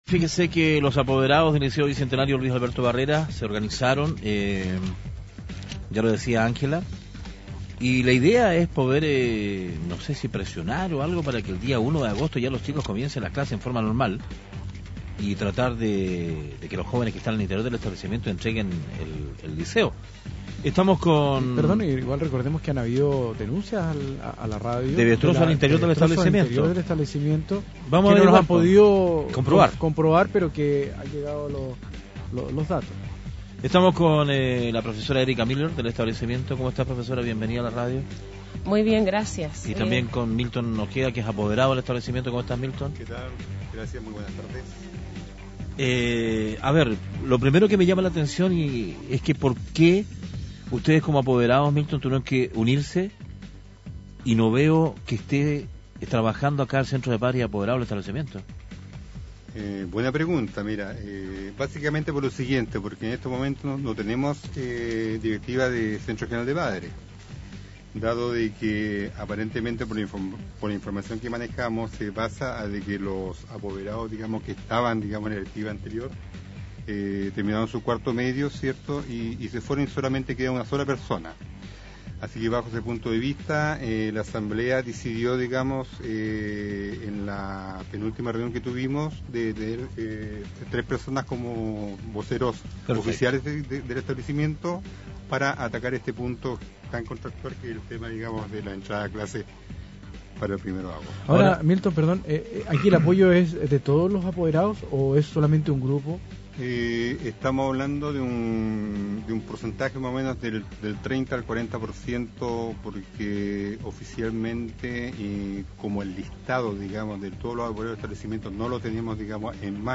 Entrevistas de Pingüino Radio - Diario El Pingüino - Punta Arenas, Chile
Max Salas, Gobernador de Última Esperanza